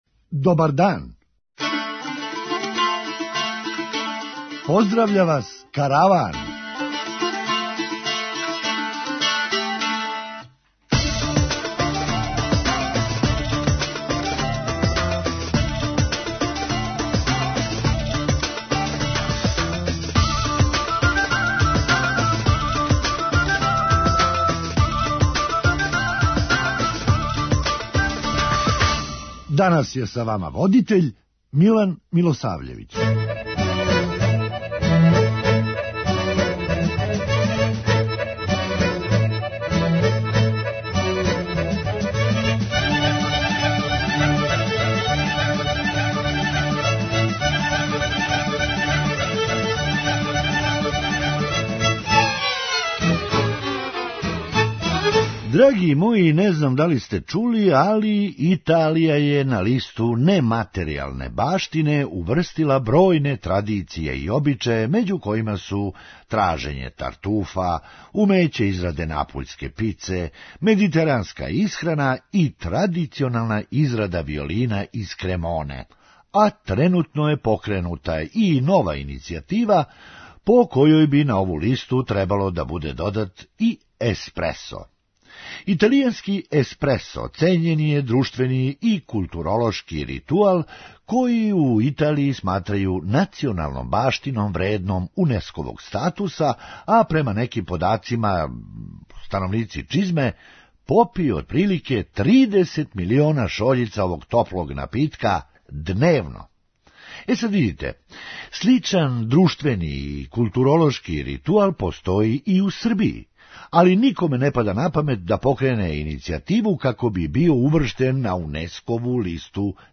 Хумористичка емисија
Ту, изгледа, не помаже ни вакцинација антивирус програмом. преузми : 8.89 MB Караван Autor: Забавна редакција Радио Бeограда 1 Караван се креће ка својој дестинацији већ више од 50 година, увек добро натоварен актуелним хумором и изворним народним песмама.